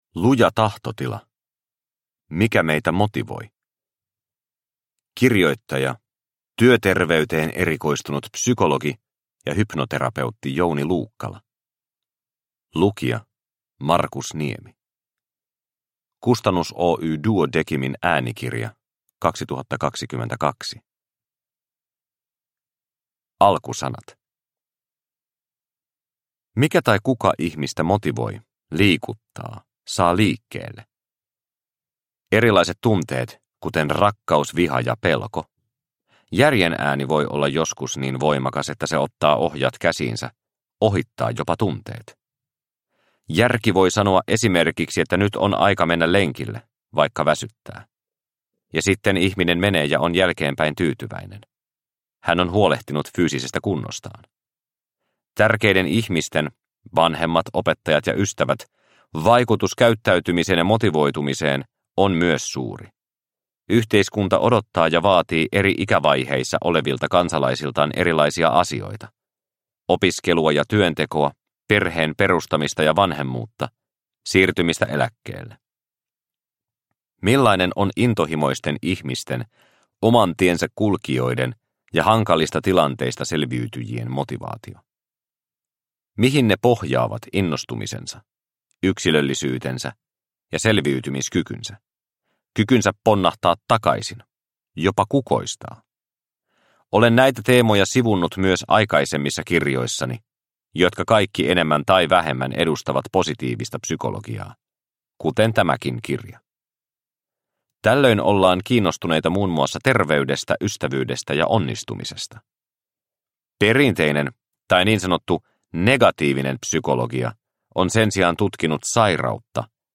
Luja tahtotila – Ljudbok – Laddas ner